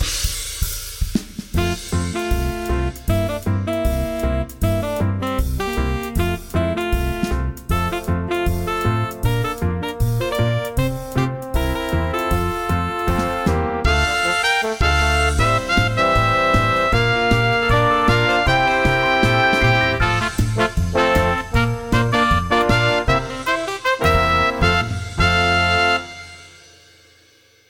そんなワクワクした特別な気持ちを小編成JAZZで制作しました。